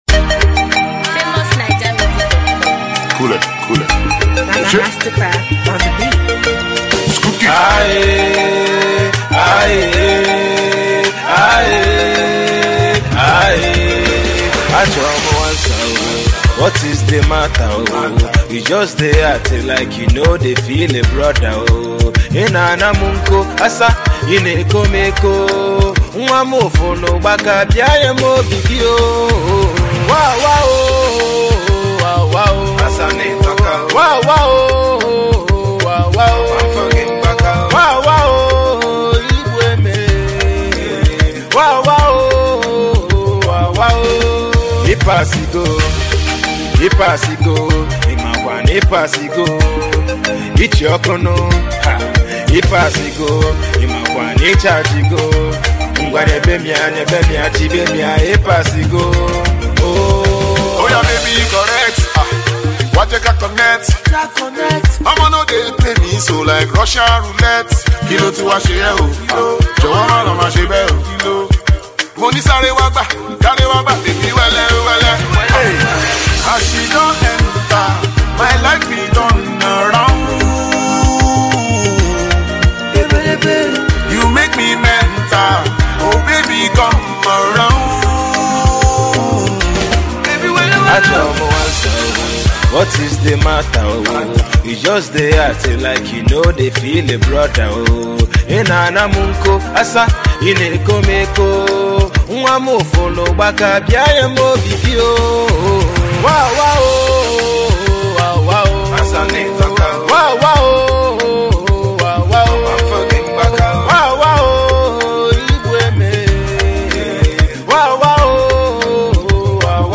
high-life influenced record